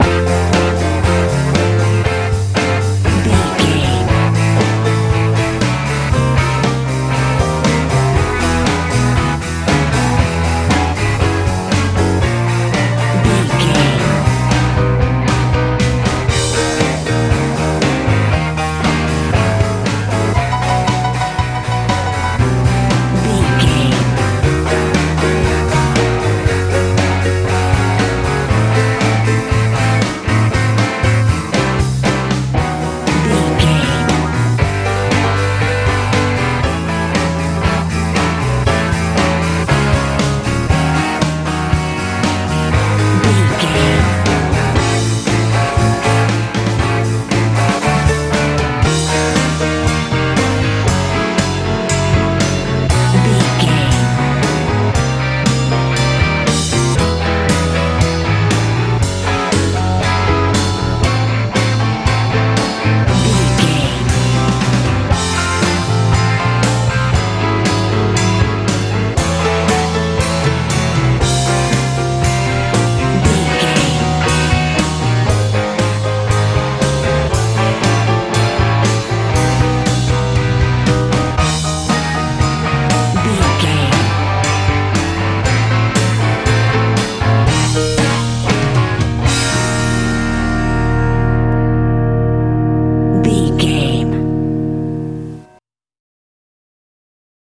med rock feel
Ionian/Major
driving
percussion
piano
electric guitar
bass guitar
drums
southern
lively
heavy